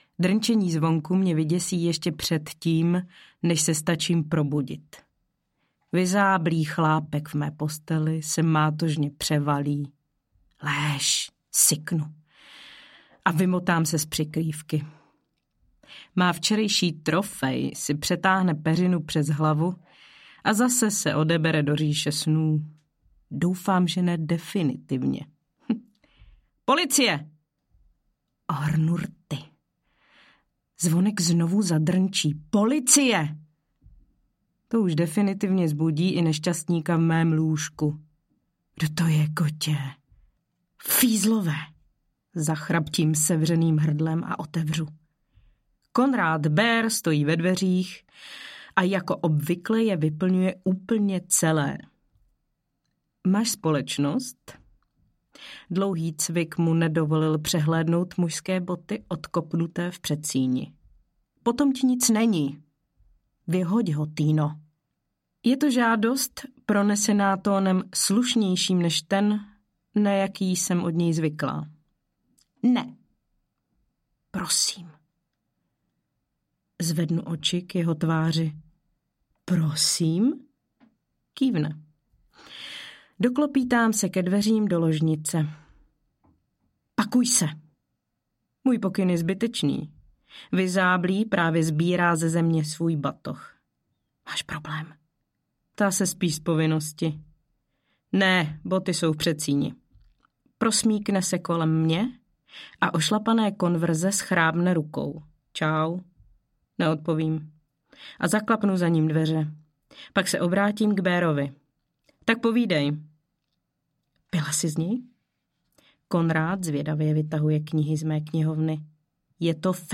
Sladká jak krev audiokniha
Audiokniha Sladká jak krev, kterou napsala Petra Neomillnerová.
Ukázka z knihy